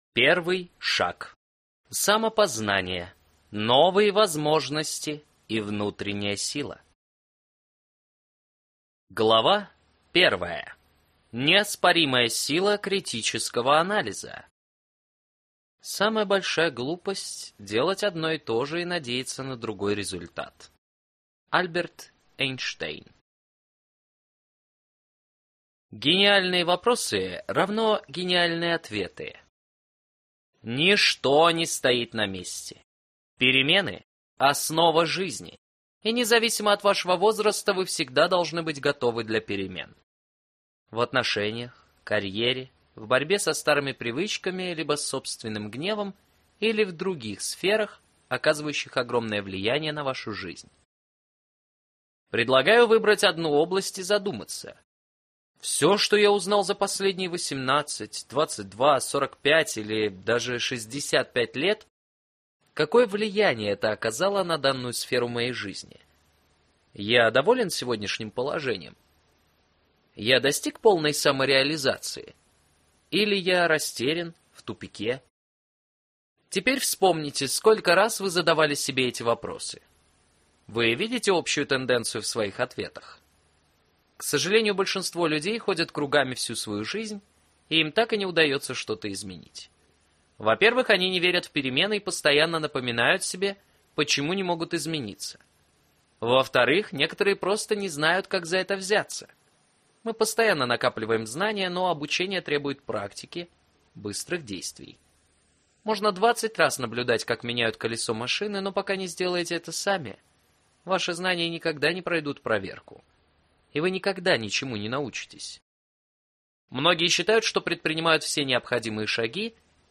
Аудиокнига Кто сказал, что ты не можешь? Ты – можешь!